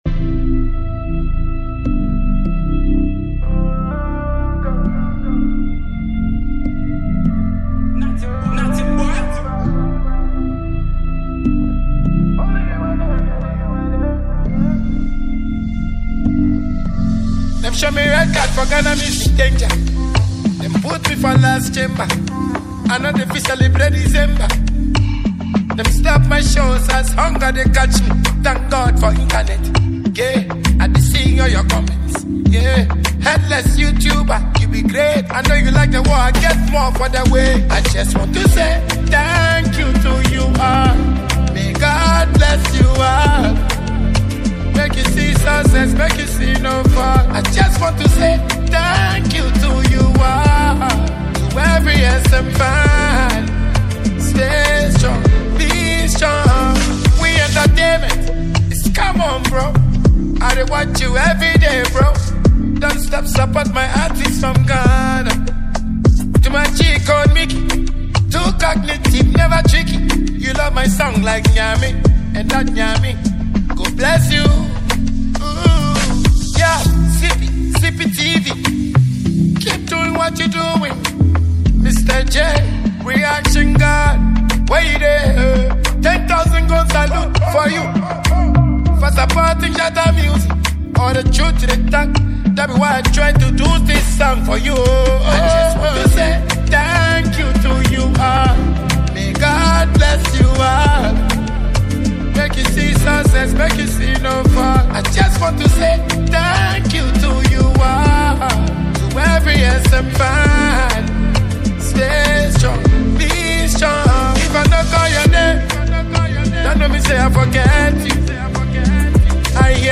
dancehall
clean and bouncy production
delivers catchy lines that feel both playful and reflective